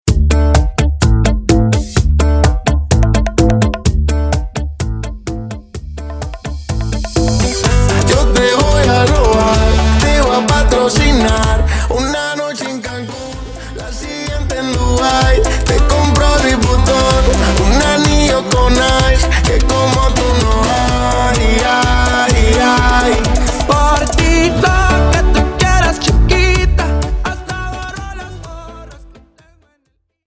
a talented Latin music remixer from Ecuador